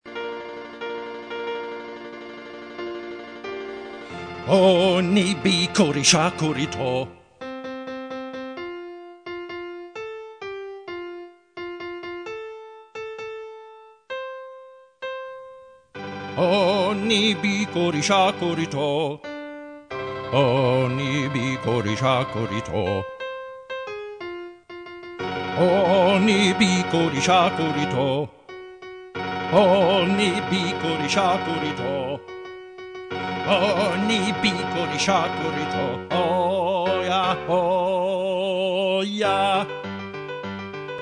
Choral parts are sung, with accompaniment and principal parts played on a computer-generated piano sound. Constantly full dynamics and mostly steady tempos are used for pitch-drilling purposes.
The part file includes your choral part played prominently, along with accompaniment and principals' vocal lines at lower volume.